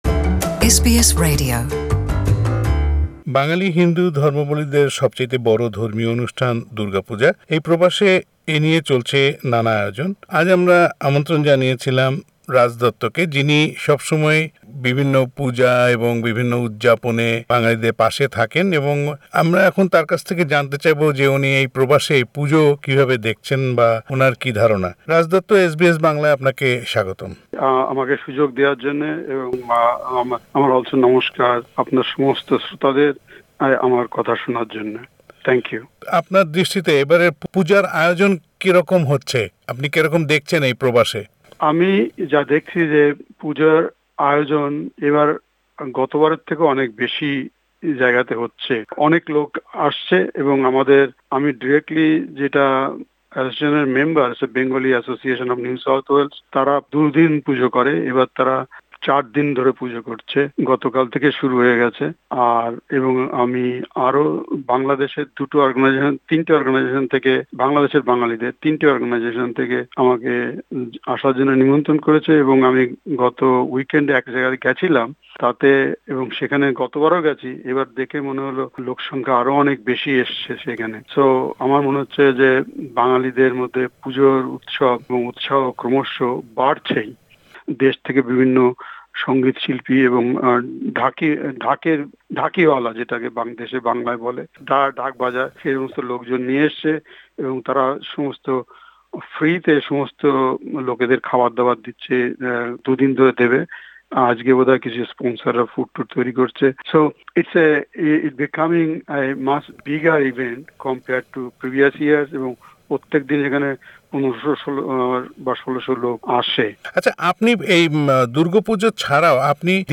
পুরো সাক্ষাৎকারটি শুনতে ওপরের অডিও প্লেয়ারটিতে ক্লিক করুন আরো পড়ুন : দুর্গাপূজার প্রস্তুতি চলছে বাংলাদেশে দুর্গাপূজার প্রস্তুতি চলছে বাংলাদেশে Share